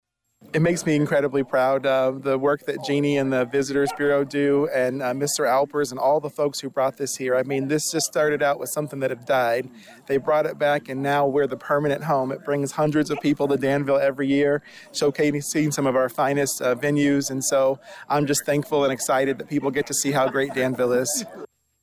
Mayor Rickey Williams, Jr. thanked those who have worked to keep the tournament in Danville….